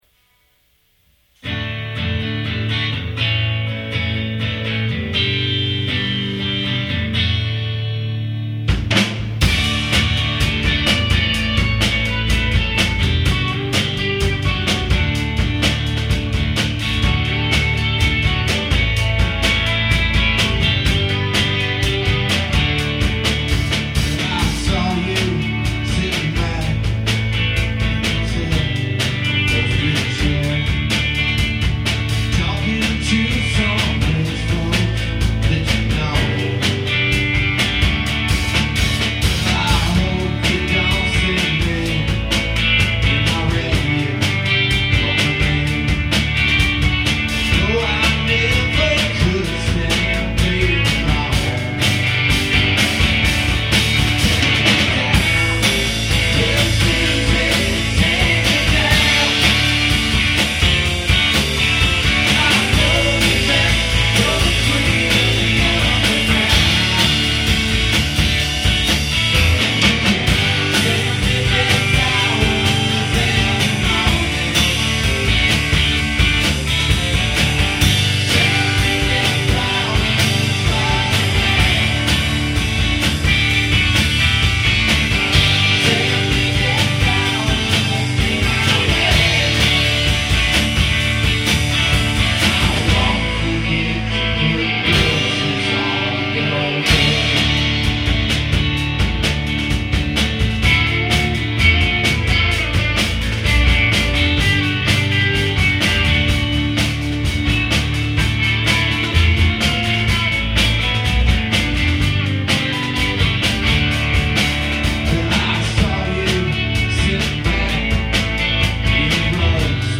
(THESE ARE LIKELY TO BE A LITTLE ROUGH AROUND THE EDGES)